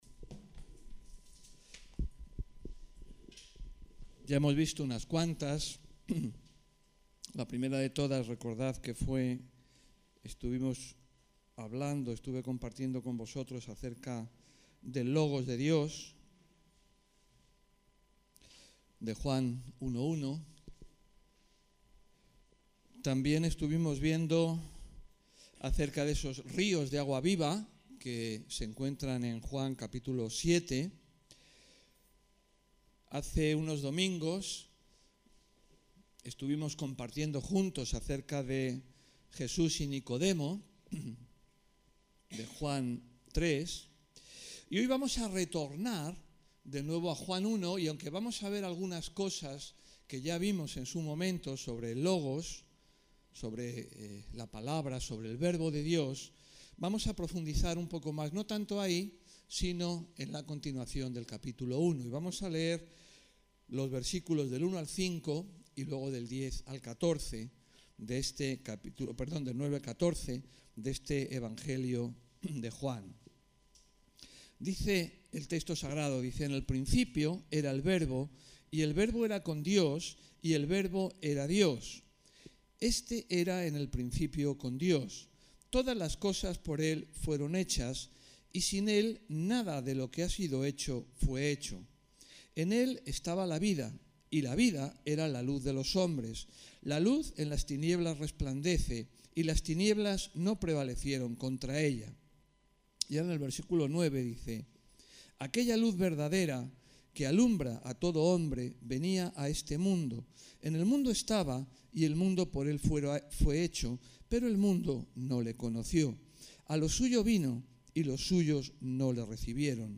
Predicaciones